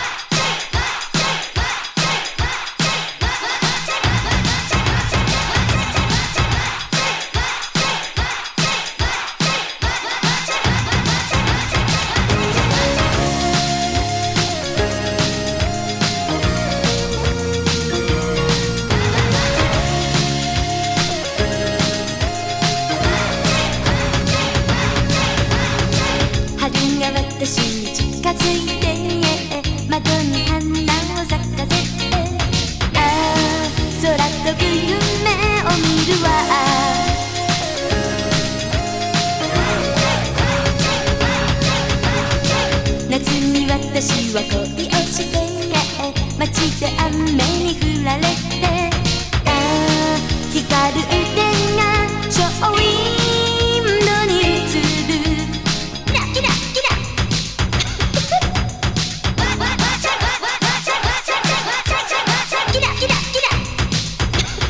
80's ロック / ポップス